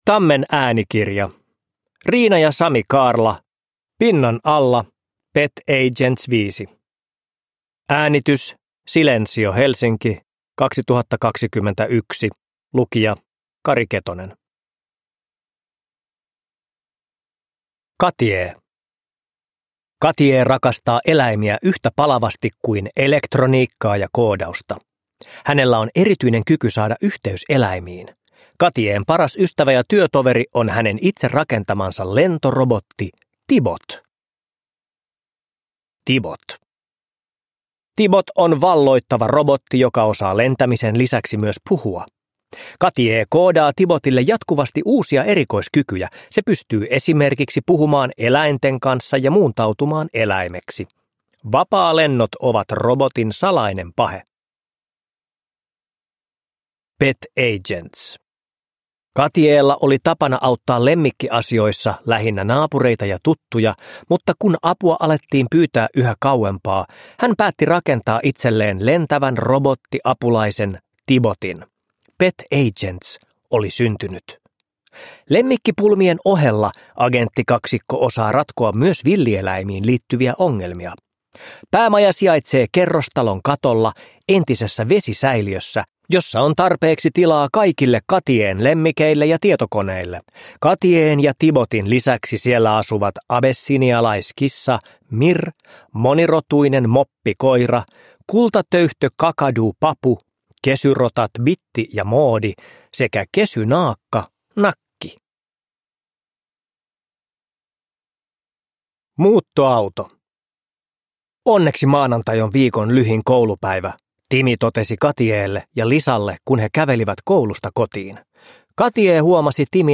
Pinnan alla. Pet Agents 5 – Ljudbok – Laddas ner